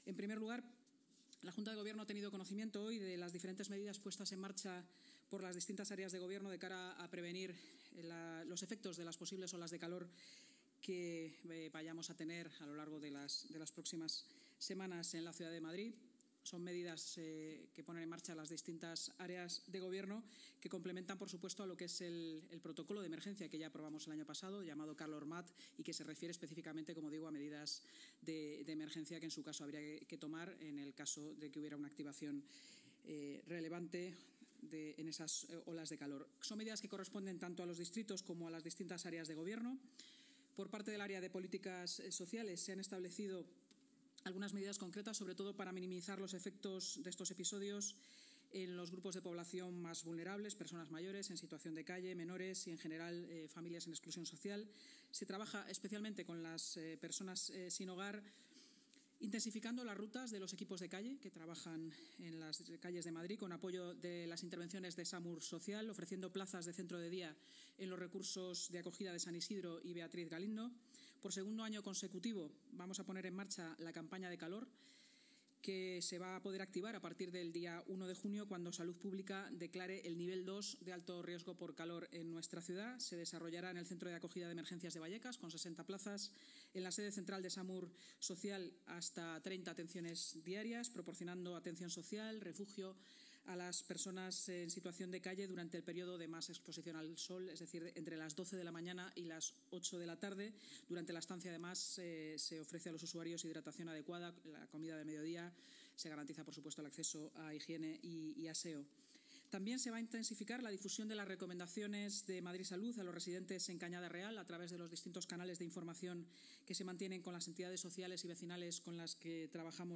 Como ha informado la vicealcaldesa y portavoz municipal, Inma Sanz, en rueda de prensa, estas medidas corresponden a los distritos y a las áreas de Gobierno de Vicealcaldía, Portavoz, Seguridad y Emergencias; Políticas Sociales, Familia e Igualdad; Urbanismo, Medio Ambiente y Movilidad; Cultura, Turismo y Deporte; Obras y Equipamientos y Economía, Innovación y Hacienda.